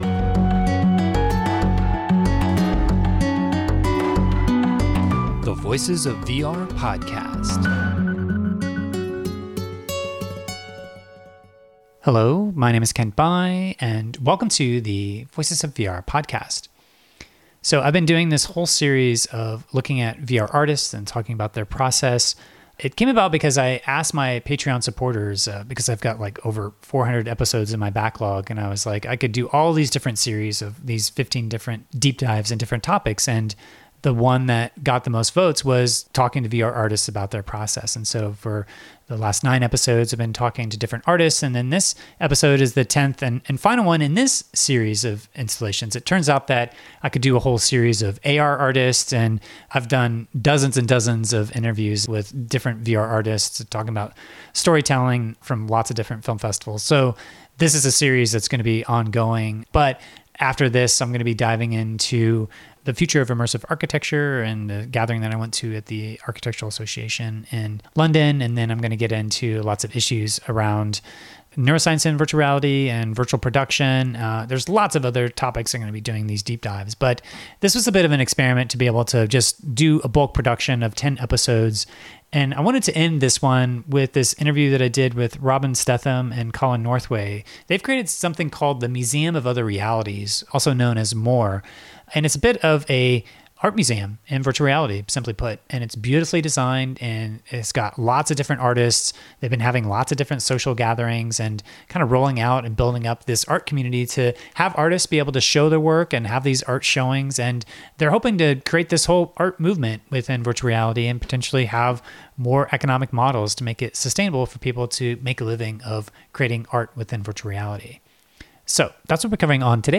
and then grab a quick 10-minute interview. We talked about the cultivating the VR artist community through social gatherings, the minimalist avatar representations, whether or not there are genres of art that are emerging yet, and future goals toward cultivating a viable economic ecosystem that can sustain virtual reality art.